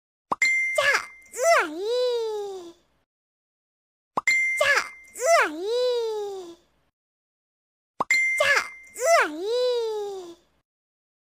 เสียงข้อความเด้ง จะอึ๋ยย
หมวดหมู่: เสียงเรียกเข้า